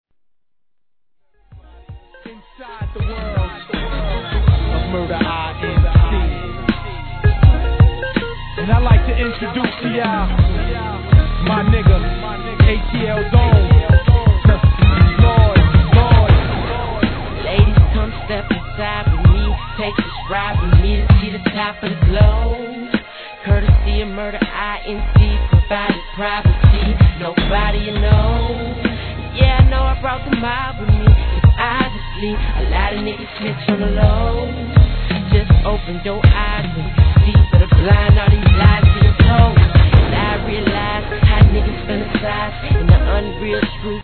HIP HOP/R&B
心にグッと響くMID TUNE!!!